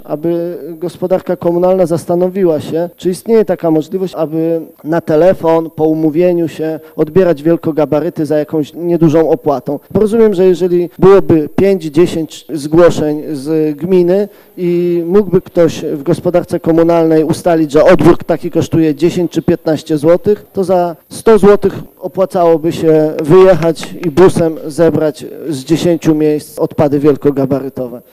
Mówi wiceprzewodniczący Rady Miejskiej Krzysztof Wójcicki: